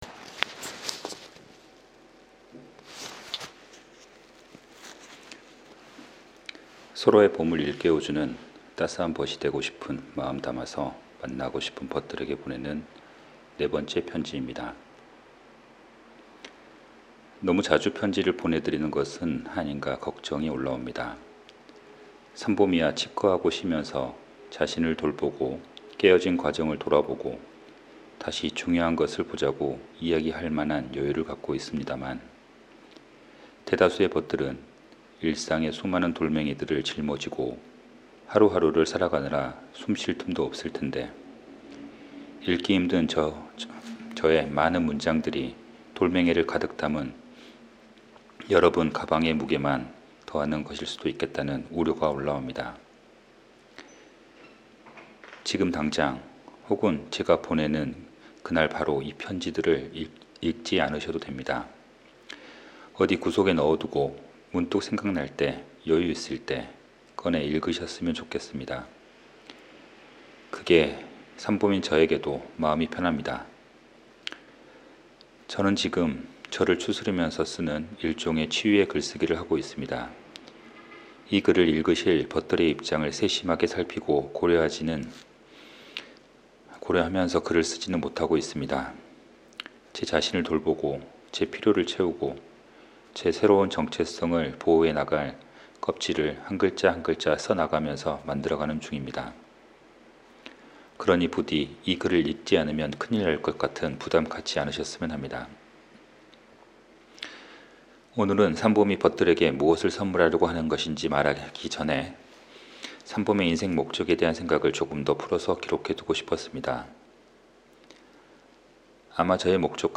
그리고 조금 긴 글이라, 여기에 글는 올려두지 않은 편지 하나 녹음파일로 공유해둡니다.